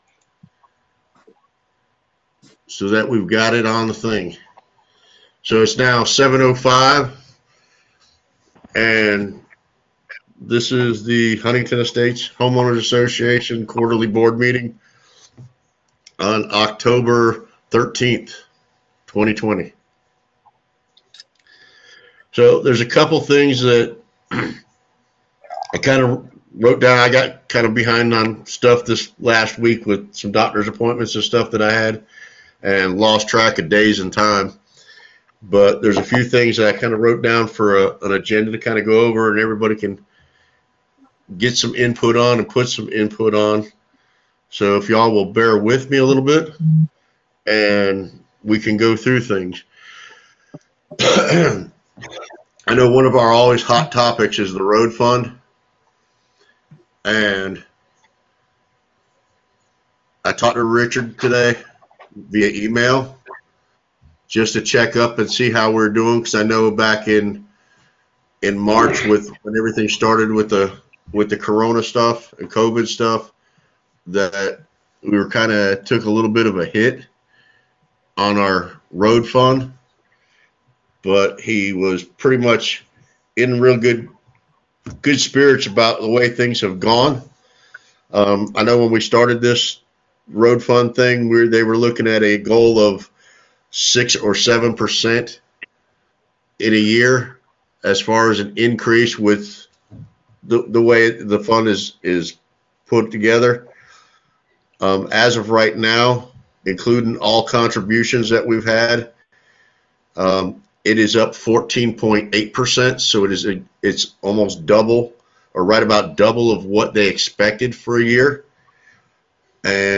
October2020BoardMeetingAudio.m4a